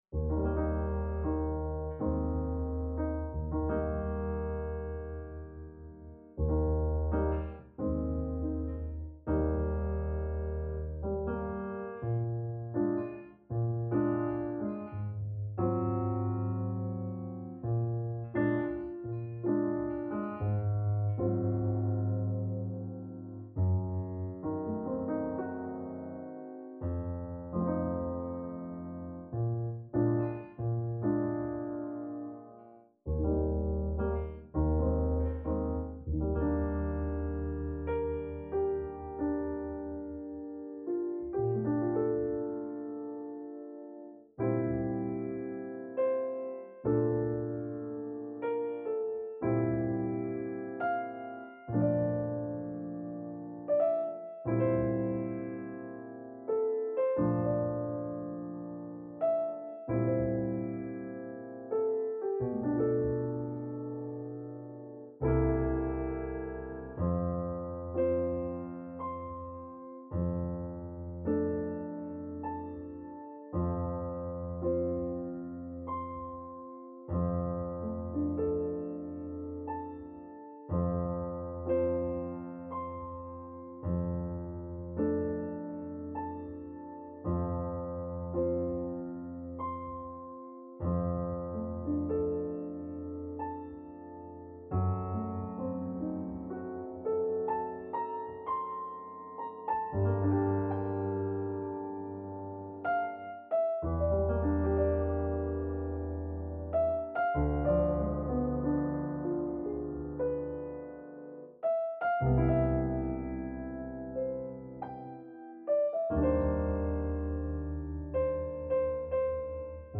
Adagio [70-80] plaisir - piano - amour - diner - bien-etre